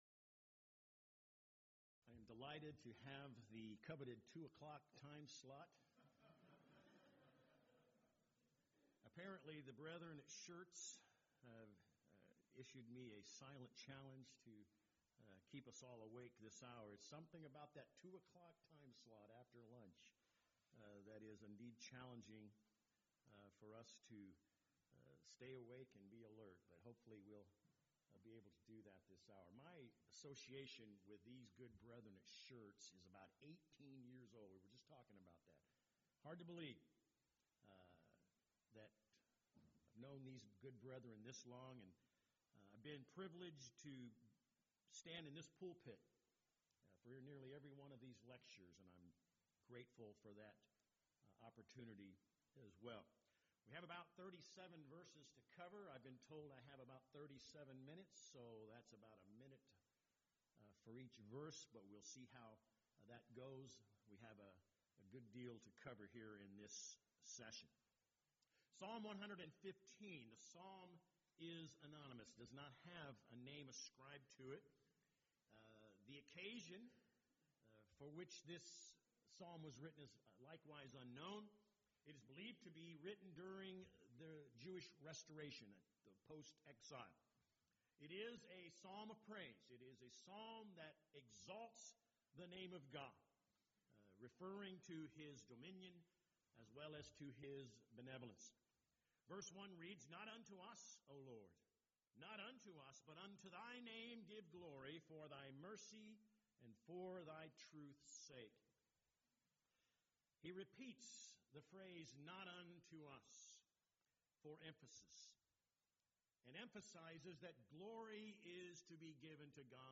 Event: 15th Annual Schertz Lectures Theme/Title: Studies in the Psalms, Volume 2